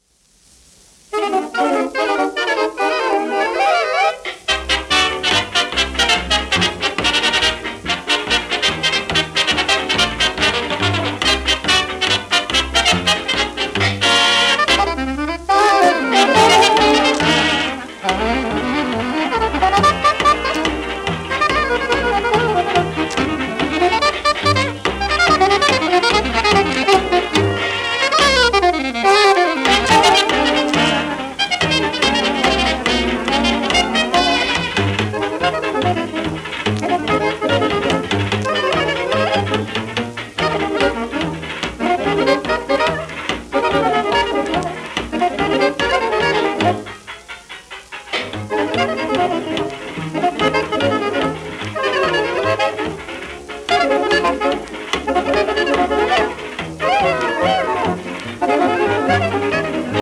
w/オーケストラ
シェルマン アートワークスのSPレコード